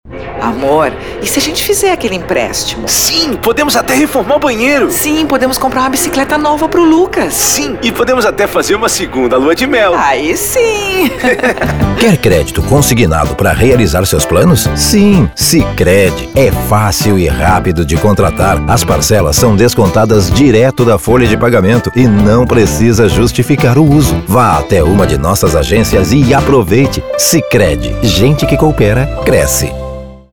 Feminino
Voz Caricata 00:30